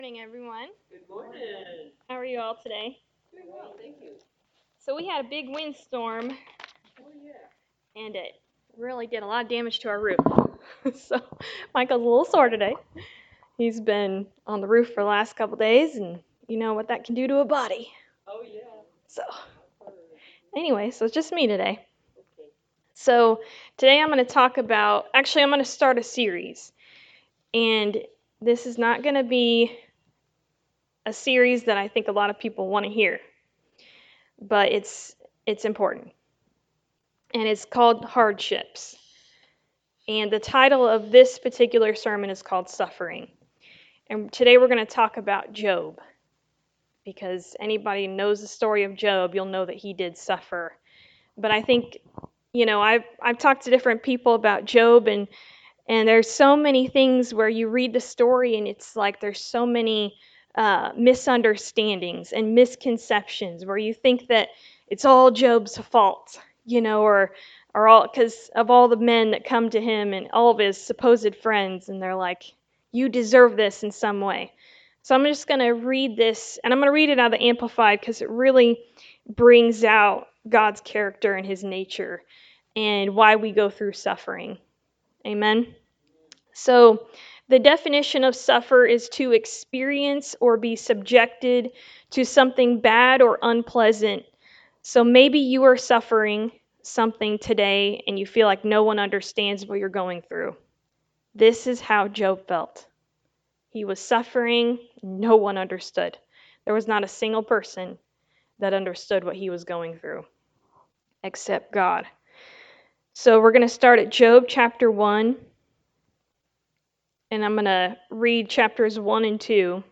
Job 1-4 Service Type: Sunday Morning Service Suffering is not pleasant.
Sunday-Sermon-for-August-4-2024.mp3